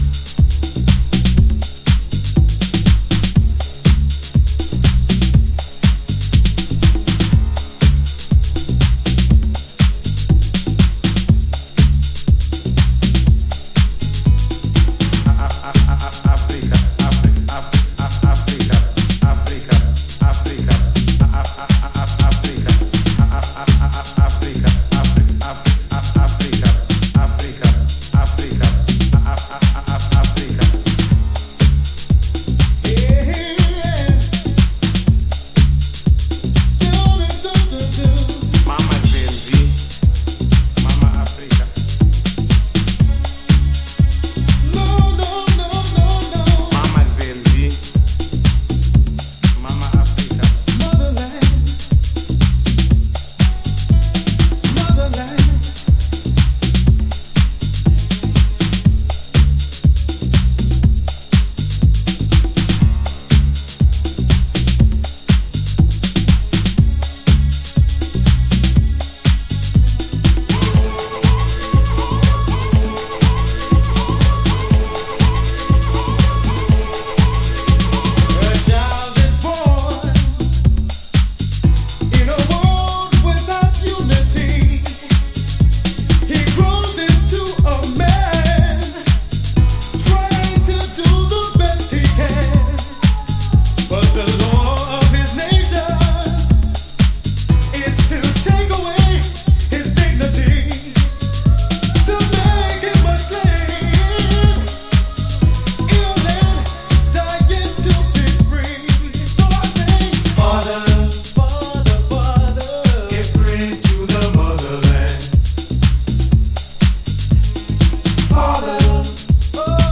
Early House / 90's Techno
マーシーナリーに表現されるアフロと当時のハウスの本気が伝わる熱量高いヴォーカルが滾るNY HOUSE代表曲。